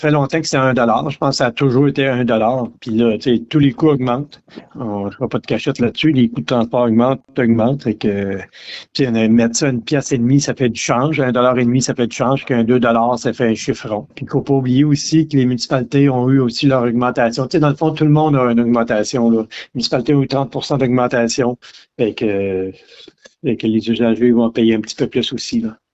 Le préfet de la MRC, Mario Lyonnais, a justifié cette hausse.